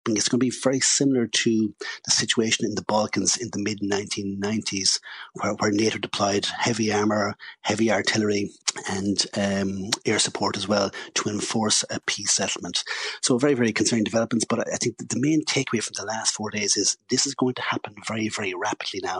Former Independent, Kildare South TD Cathal Berry says countries will have to step up their defence budgets;